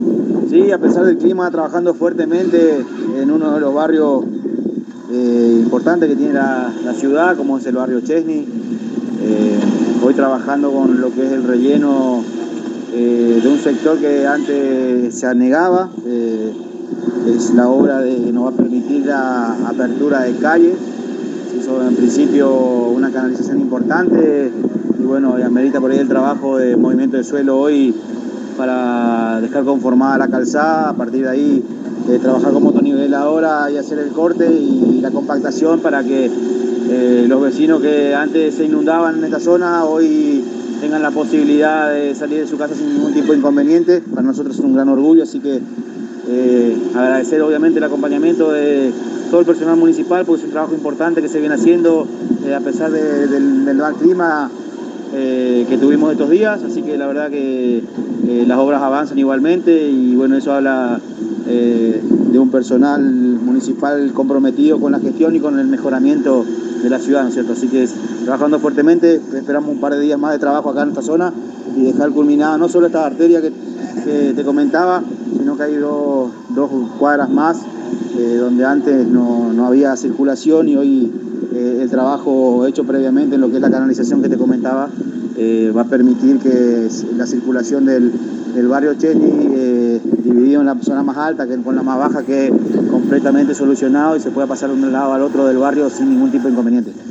El Secretario de Obras Públicas de Apóstoles Juan José Ferreyra en diálogo exclusivo con la ANG relató que se continúan con las aperturas de calle en el Barrio Czesny luego del gran trabajo de canalización lo que permite la transitabilidad en todo el barrio y la conexión entre la parte alta y baja es perfecta.